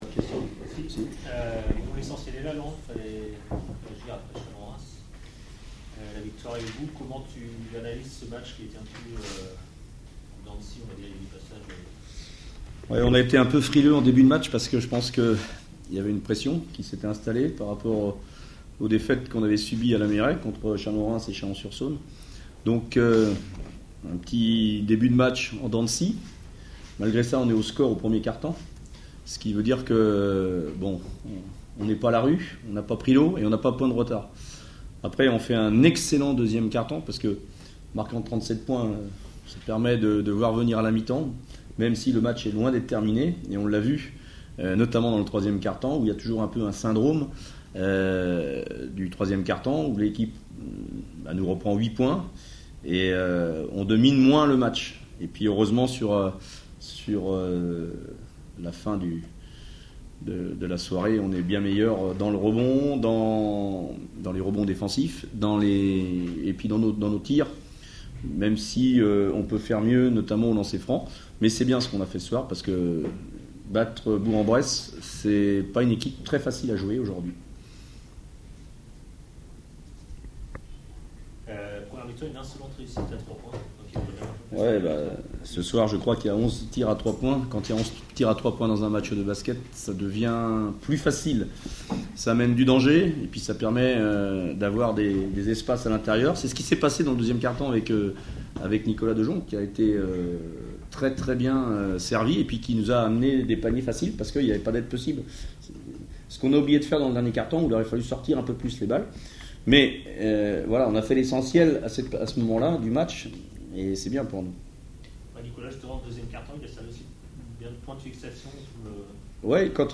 Cholet vs JL : écoutez les réactions d'après-match au micro Scoop - JL Bourg Basket